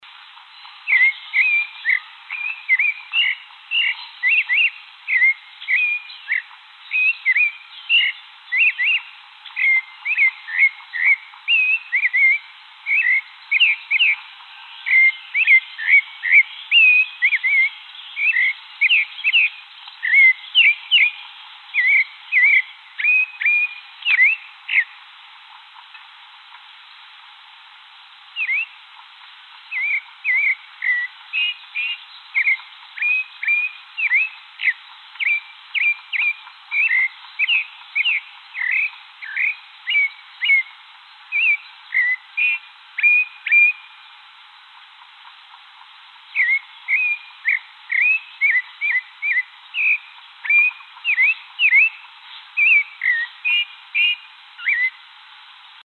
Sabiá-Barranco (Turdusleucomelas)
Enquanto o mundo ainda dorme, lá está ele, soltando notas melódicas no escuro, como se fosse o despertador da floresta.
No resto do ano, emite apenas sons de alerta, principalmente ao entardecer.